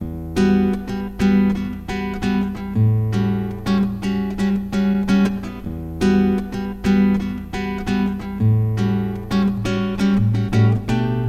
描述：电影|欢快
标签： 电吉他 弦乐器 贝司 键盘乐器 风琴 钢琴
声道立体声